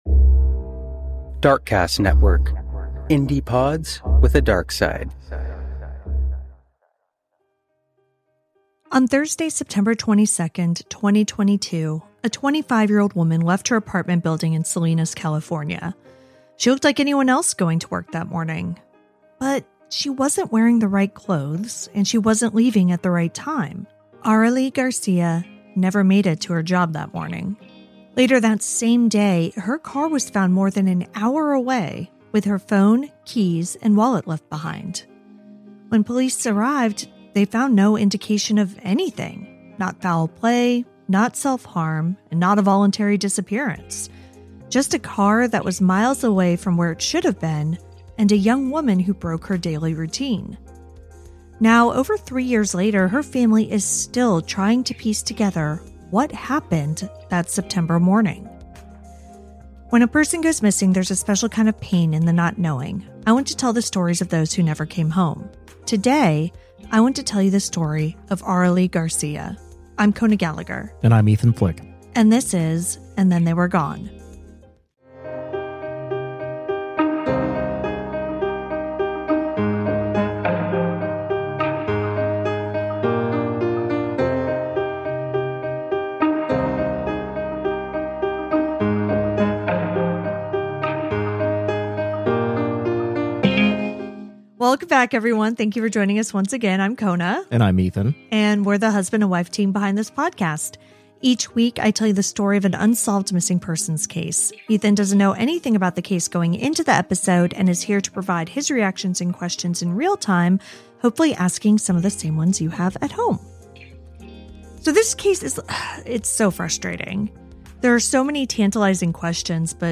Genres: Documentary